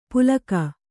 ♪ pulaka